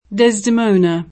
vai all'elenco alfabetico delle voci ingrandisci il carattere 100% rimpicciolisci il carattere stampa invia tramite posta elettronica codividi su Facebook Desdemona [ de @ d $ mona ; ingl. de @ dimë ^ unë ] pers. f.